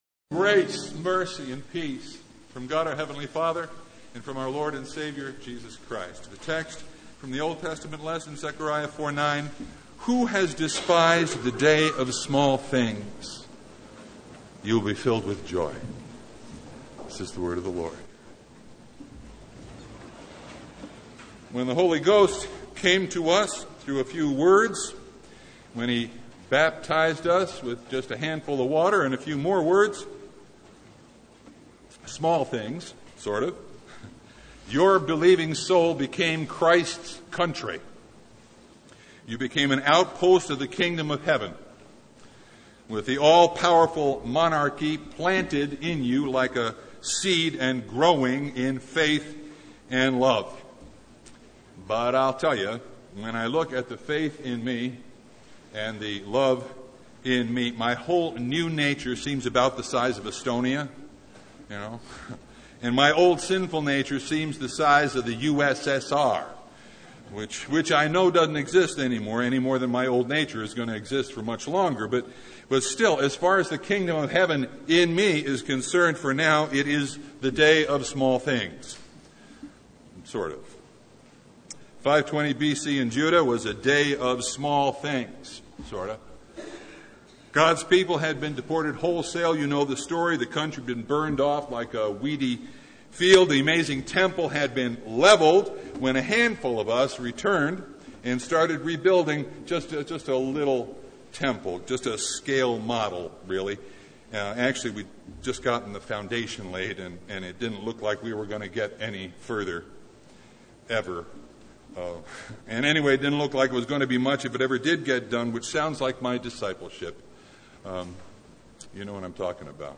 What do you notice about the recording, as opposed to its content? Passage: Zechariah 4:9 Service Type: Sunday